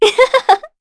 Veronica-Vox_Happy3_kr.wav